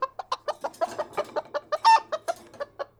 chicken-heck-sound